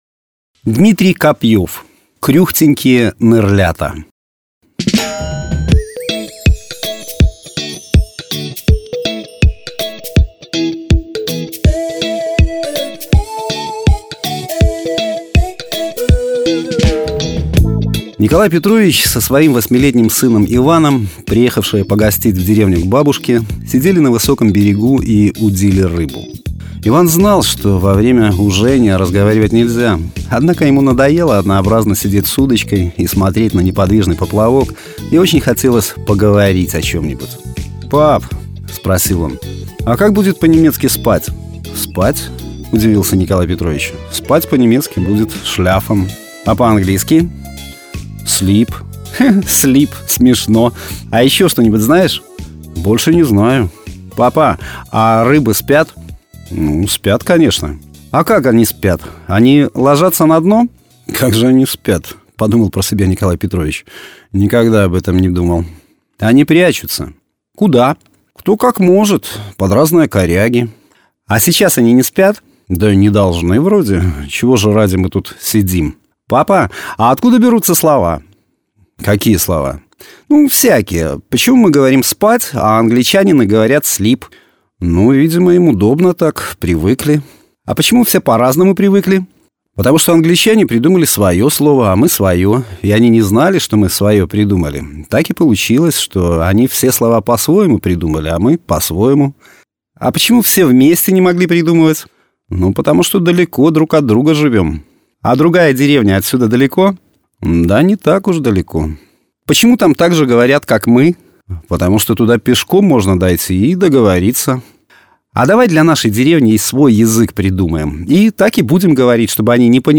Аудиорассказ
Жанр: Современная короткая проза
Издательство: Радио «Гомель Плюс». Конкурс короткого рассказа
Качество: mp3, 256 kbps, 44100 kHz, Stereo